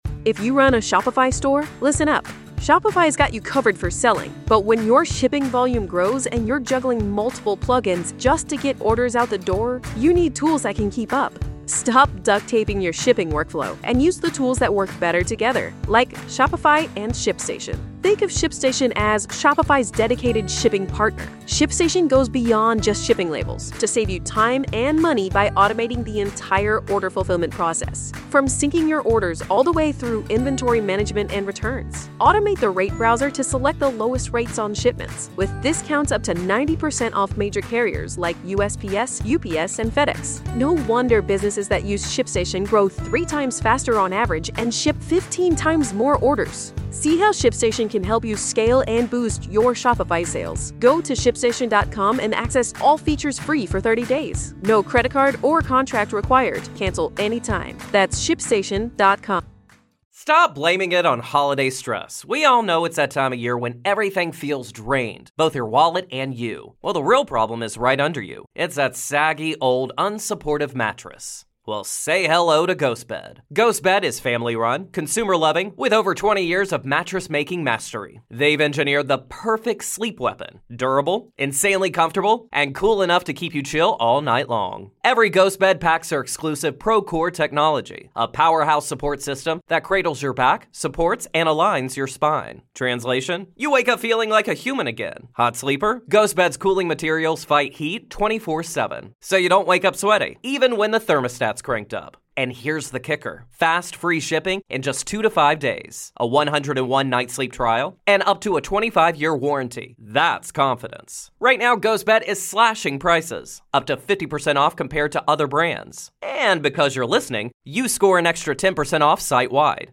We're still on corona so everyones reporting in from their homes.